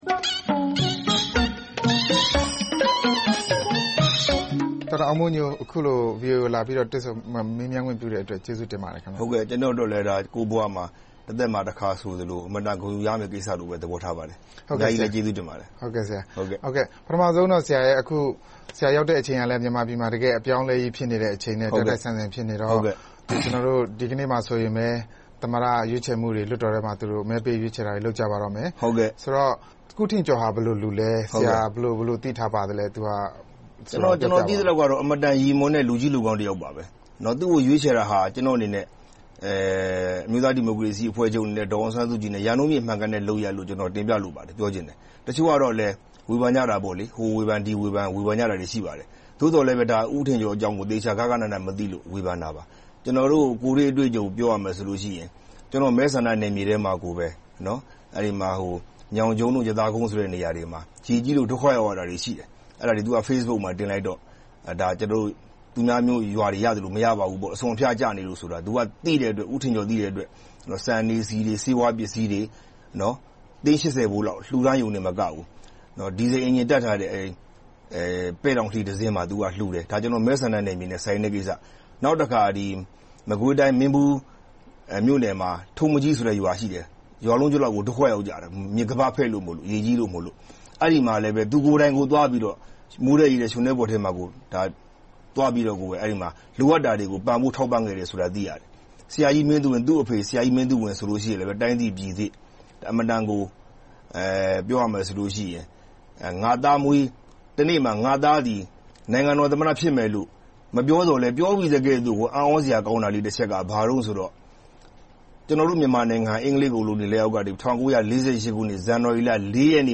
အင်တာဗျူး